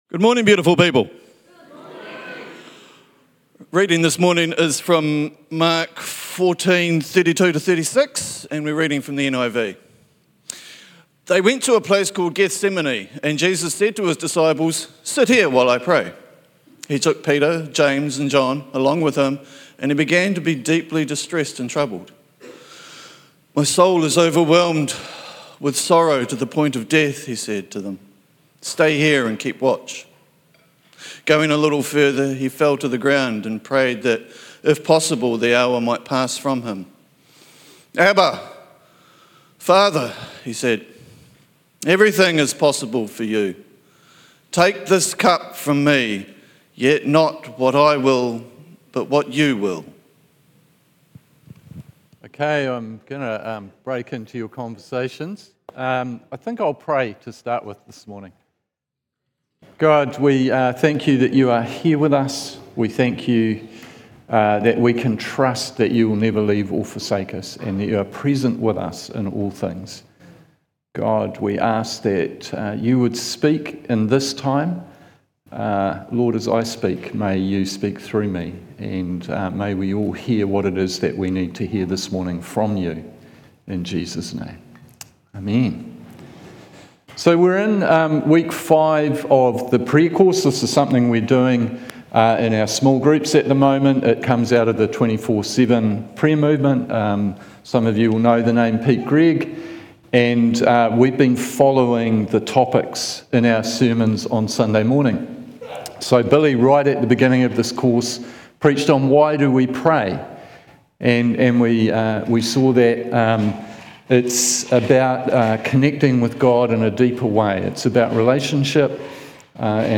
Sermons | Whanganui Anglicans